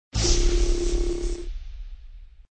SShockerShot.ogg